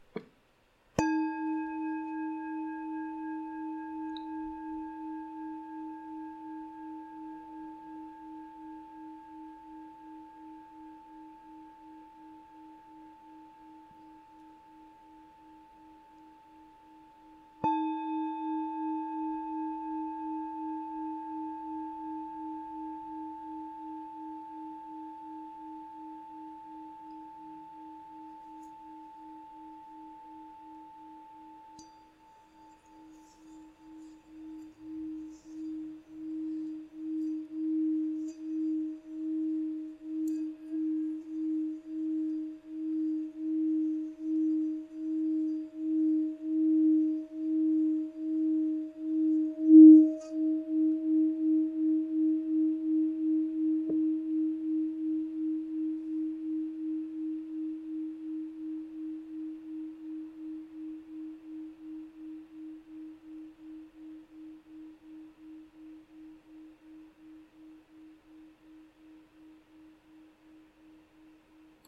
Nota Armonica LA(A) 5 887 HZ
Nota di fondo RE d4 318 HZ
Campana Tibetana Nota RE d4 318 HZ